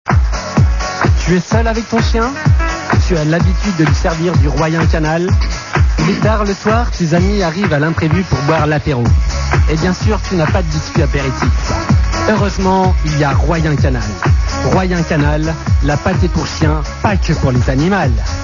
Fausse Pubs : Royin Canal